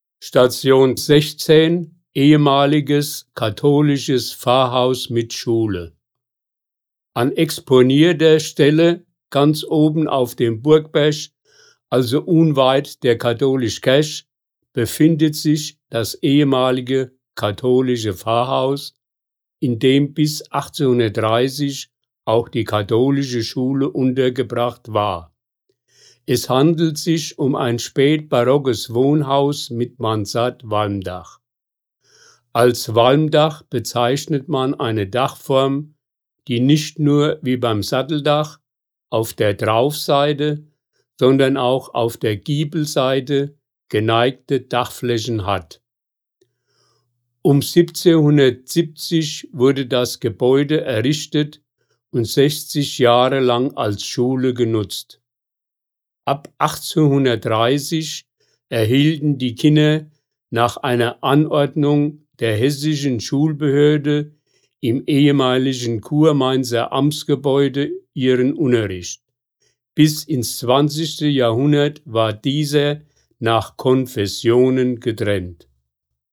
Hier können Sie die Hörfassung der Stationsbeschreibung abspielen!